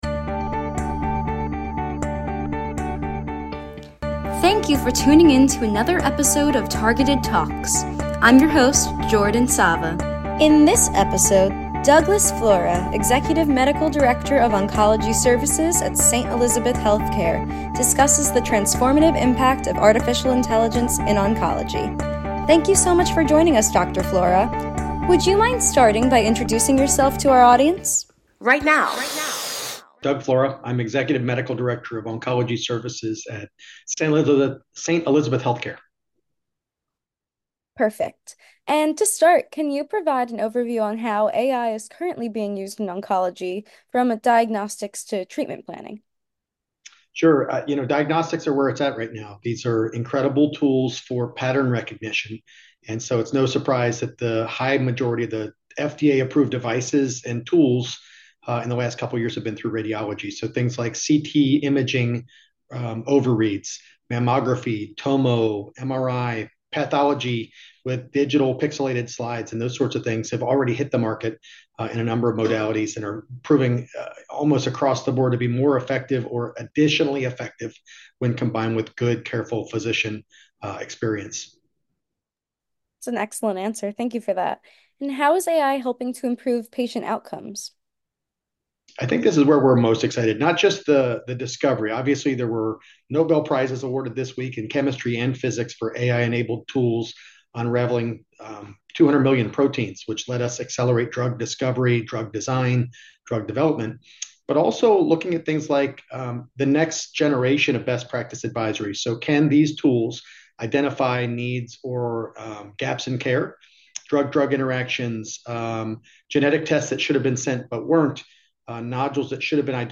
Targeted Oncology brings you Targeted Talks, a monthly podcast featuring discussions with academic and community oncologists surrounding advancements in cancer research and best practices for patient management.